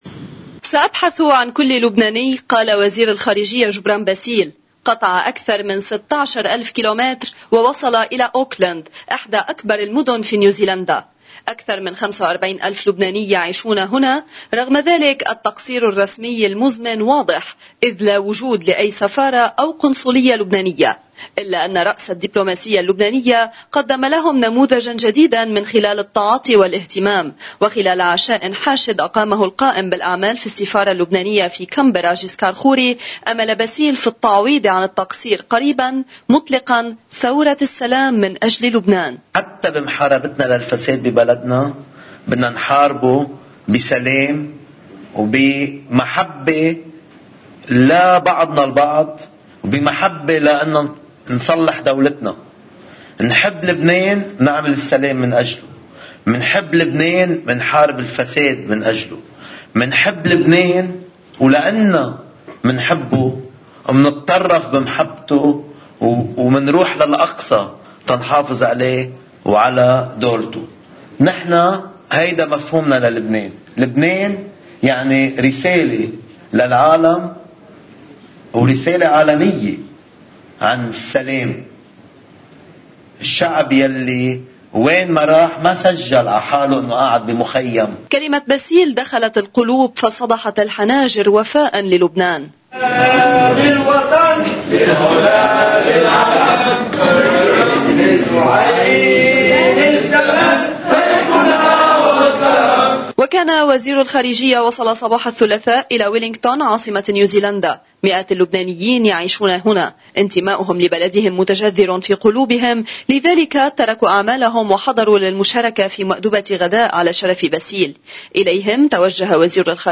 مقتطف من حديث وزير الخارجية جبران باسيل للبنانيين المنتشرين في فنزويلا: